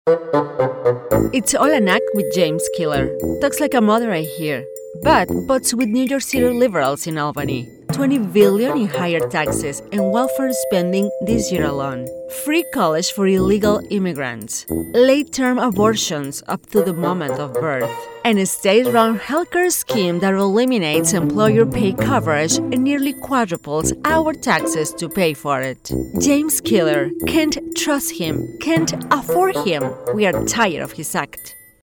Female Spanish Republican Political Voiceover
English w/SP accent